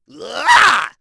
Ezekiel-Vox_Attack3.wav